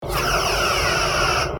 otherphaser1.ogg